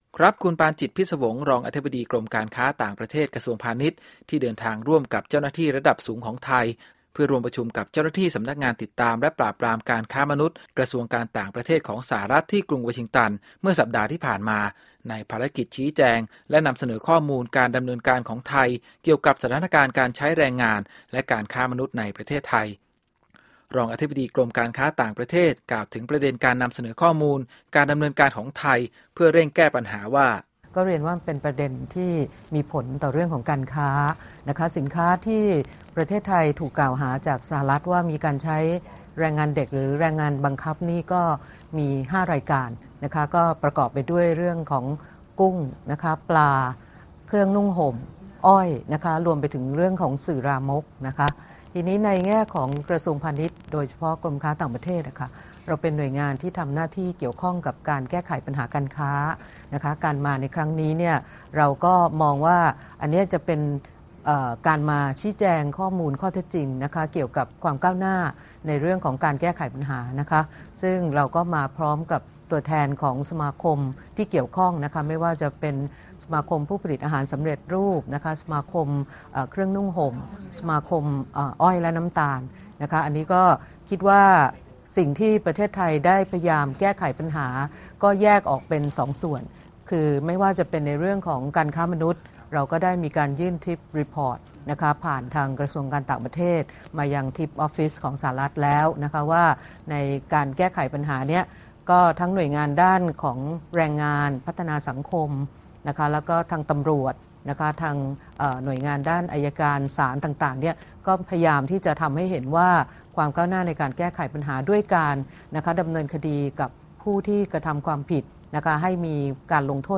Thai Interview Commerce